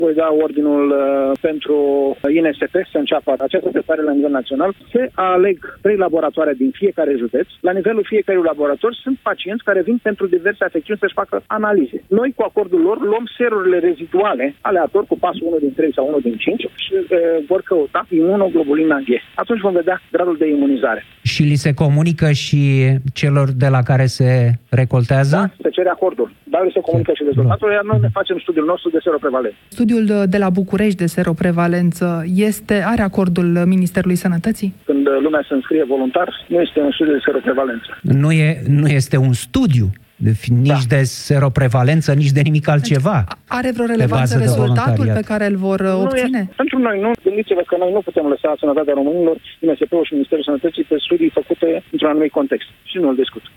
Ministrul Sănătății a anunțat, la Europa FM, în emisiunea Piața Victoriei, că va emite, marți, ordinul de începere a testării de seroprevalență la nivel național, în urma căreia vom afla câți români au capătat imunitate la noul coronavirus.